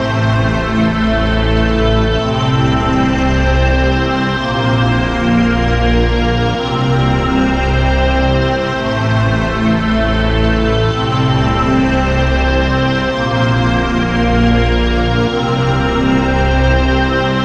回声Trance Bass Progression Loop
描述：带有延迟和混响的Echoey Trance低音合成器，调子是D小调，循环形成4小节的进展，与奶油低音样本的模式相同。
Tag: 135 bpm Trance Loops Bass Loops 1.20 MB wav Key : Unknown